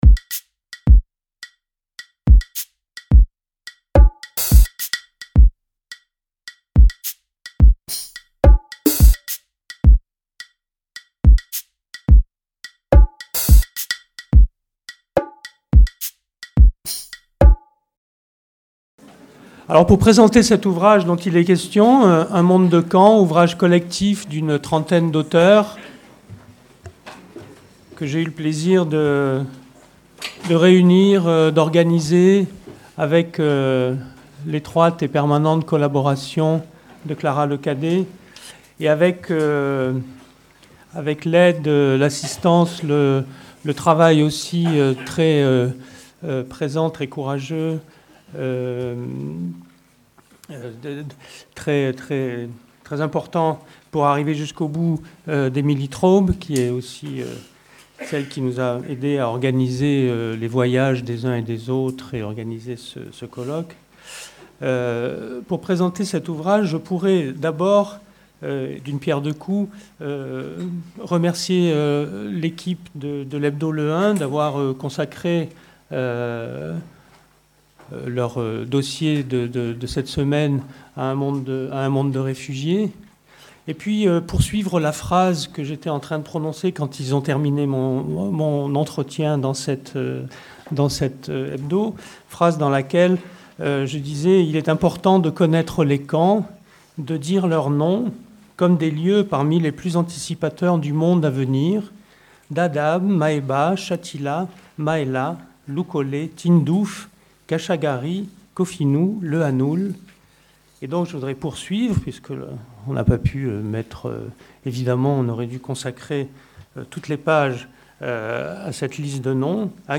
Ce colloque vise à dépasser la représentation des camps comme des espaces figés, en envisageant leur évolution dans des temporalités souvent longues.